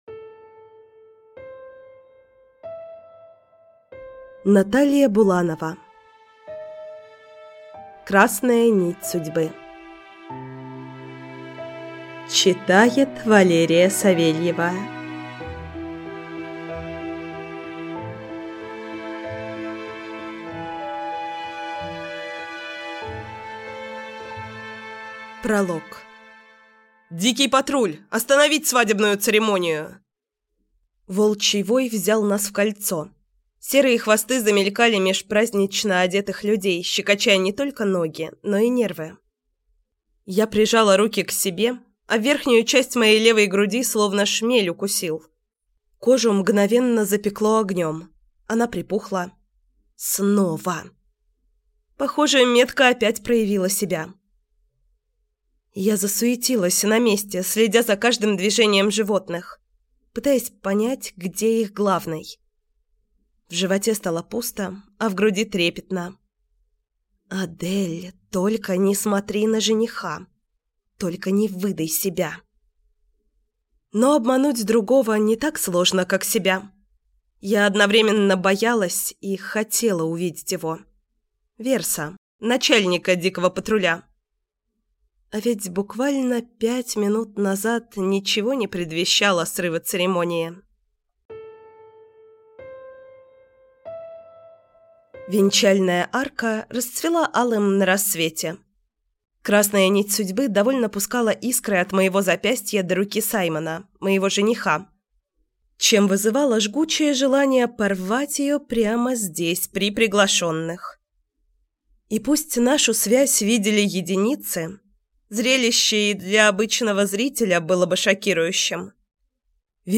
Аудиокнига Красная нить судьбы | Библиотека аудиокниг
Прослушать и бесплатно скачать фрагмент аудиокниги